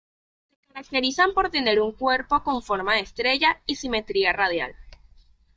Pronounced as (IPA) /simeˈtɾia/